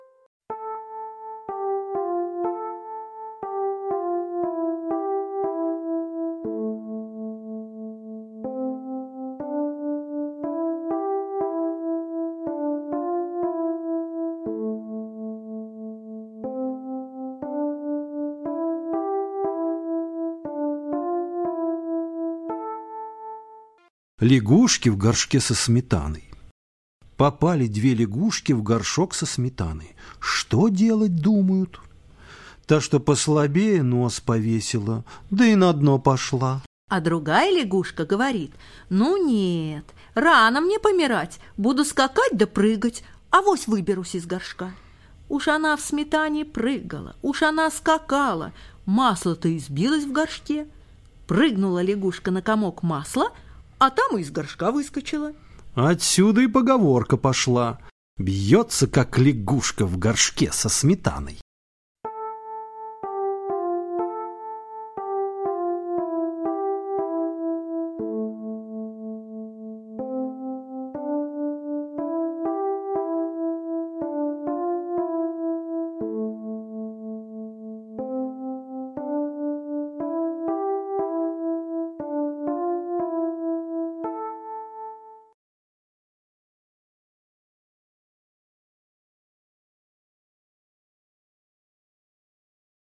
Лягушка в горшке со сметаной – латышская аудиосказка